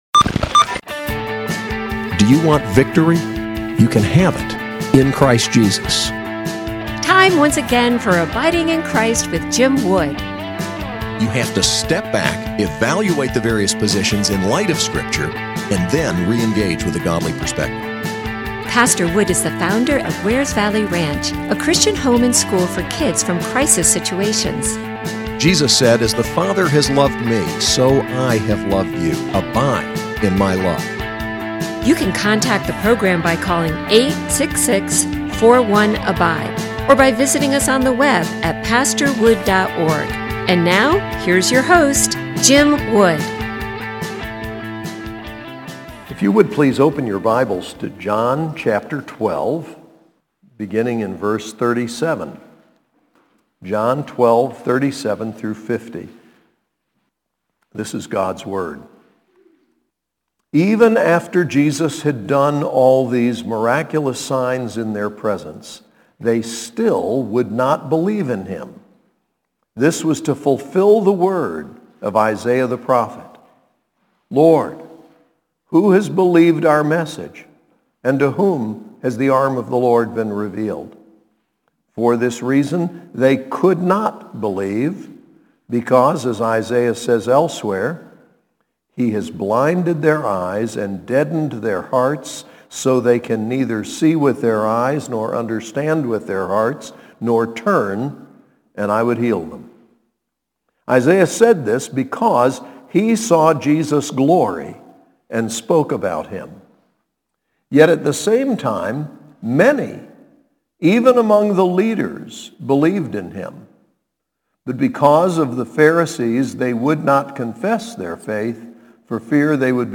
SAS Chapel: John 12:37-50